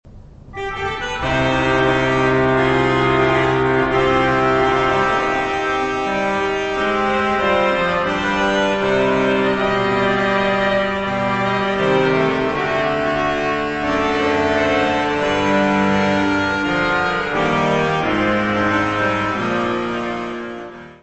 : stereo; 12 cm
Orgão histórico da Abbaye de Saint-Michel en Thiérache
orgão
Music Category/Genre:  Classical Music